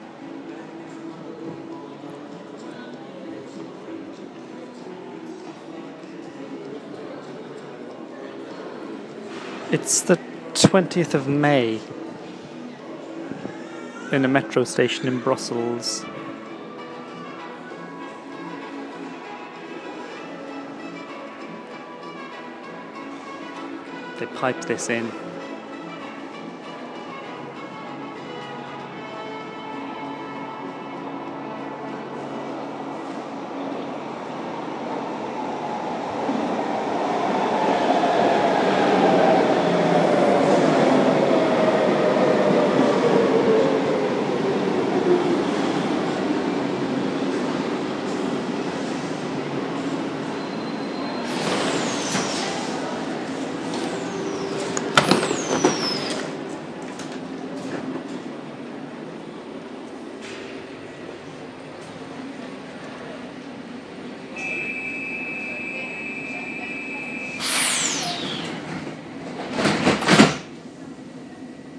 Muzak in a Brussels Metro station